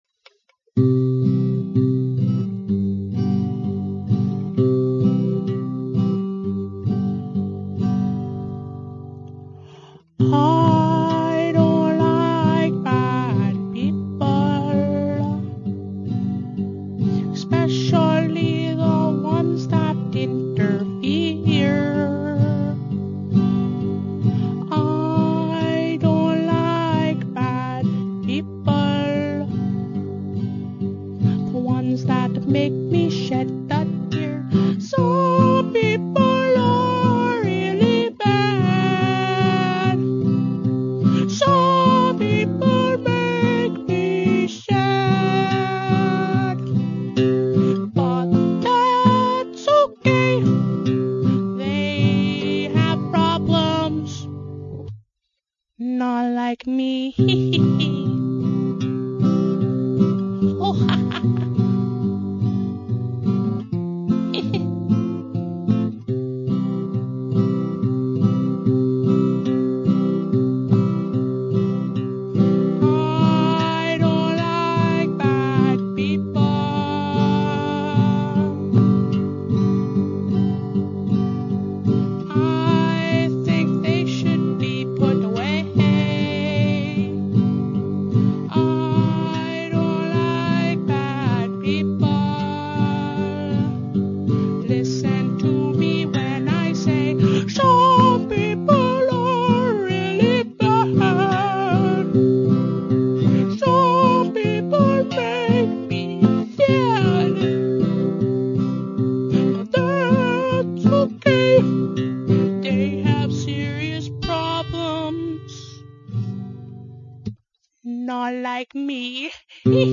I like the giggling.